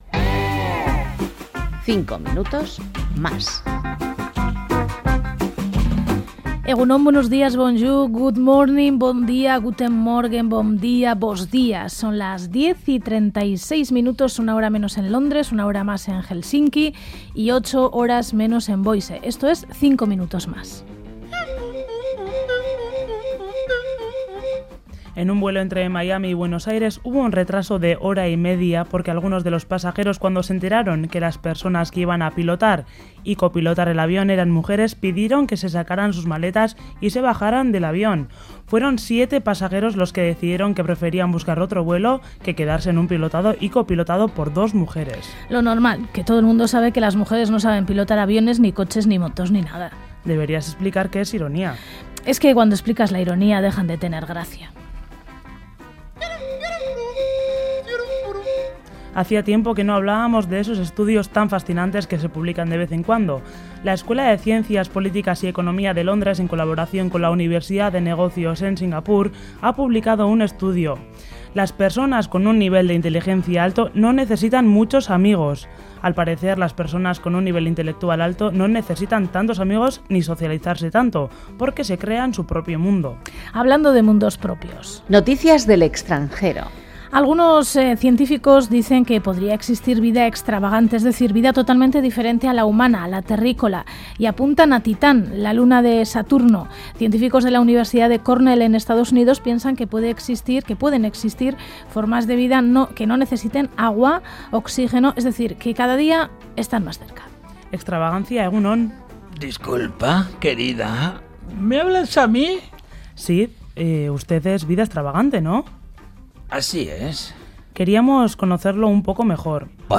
Informativo| 5 minutos mas| RADIO EUSKADI